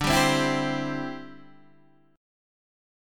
D 9th Suspended 4th